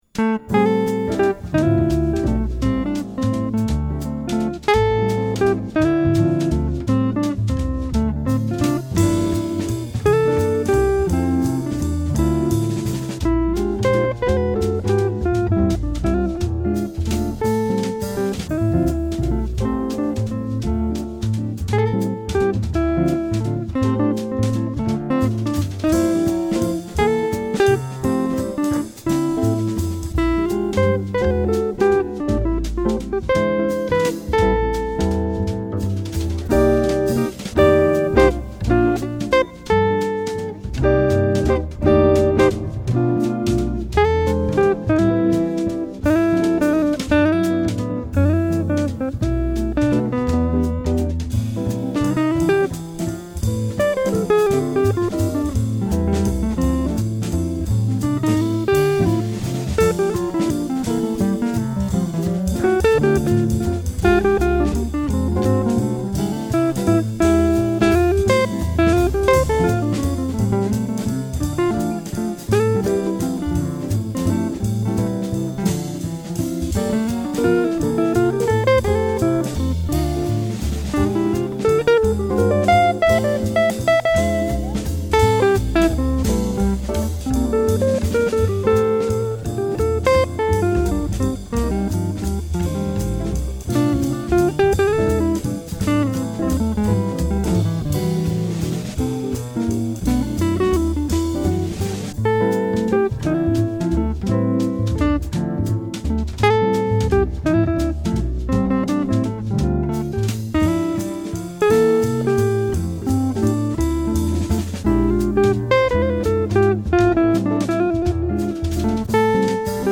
Guitar Works
An Original Jazz Waltz